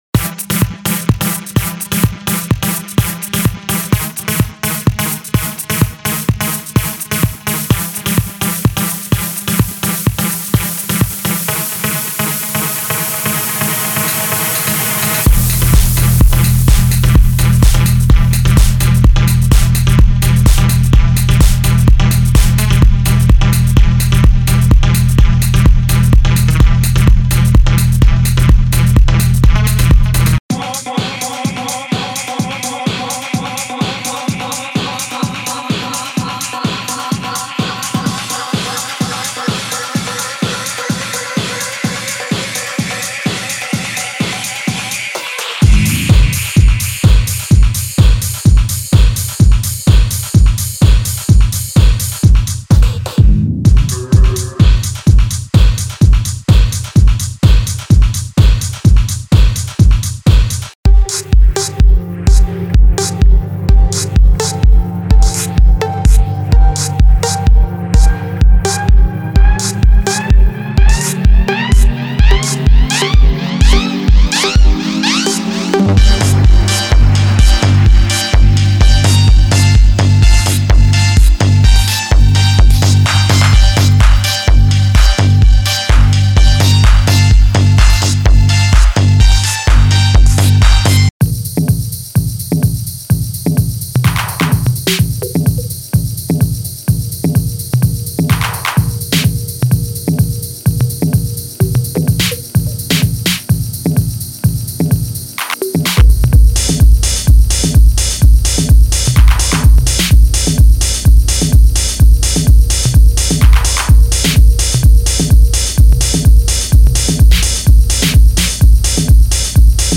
Genre: Techno